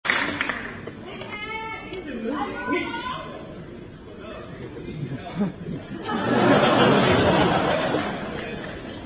２００４年１０月２３日　渋谷公会堂　２階
聴衆のどよめきが上がる。
「Are You OK?」にNo! No!と２度答える。